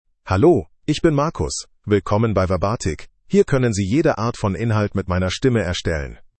MarcusMale German AI voice
Marcus is a male AI voice for German (Germany).
Voice sample
Listen to Marcus's male German voice.
Male
Marcus delivers clear pronunciation with authentic Germany German intonation, making your content sound professionally produced.